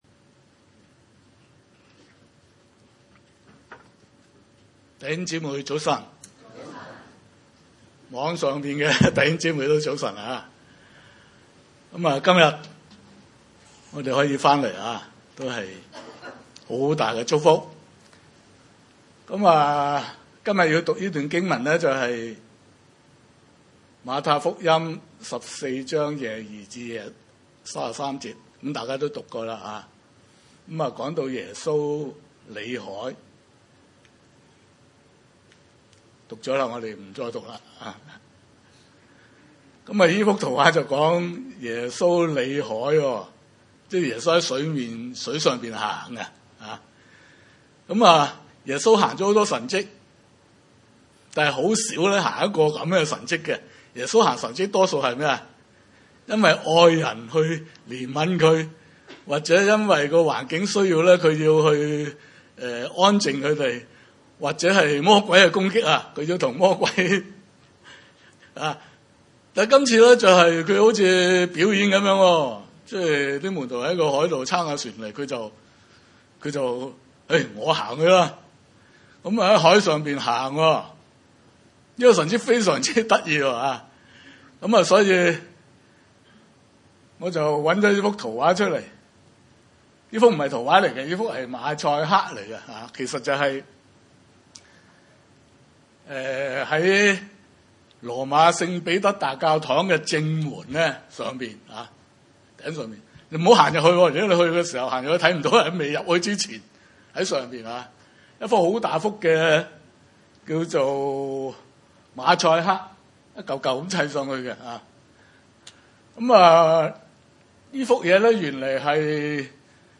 2023-10-22 經文: 馬太福音 14：22—33 崇拜類別: 主日午堂崇拜 22 耶穌隨即催門徒上船，先渡到對岸，等他叫眾人散去。